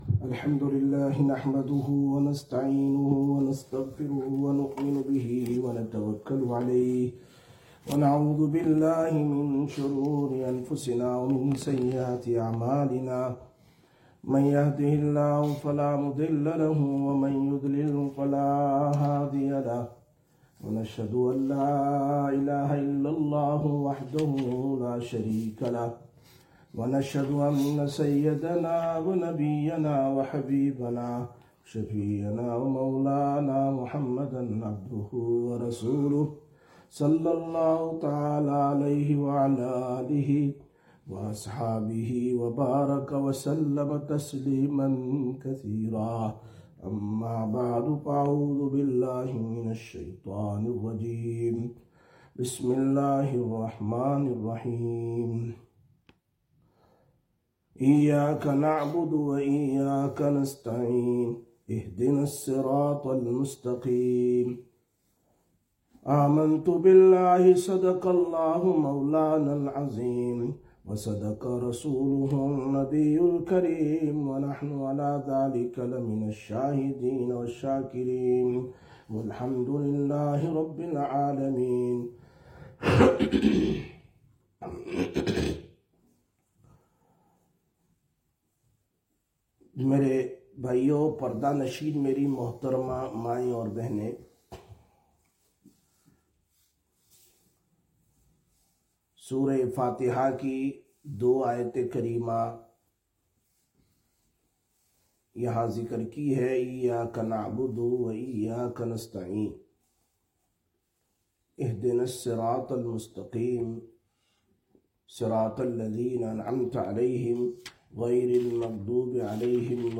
23/04/2025 Sisters Bayan, Masjid Quba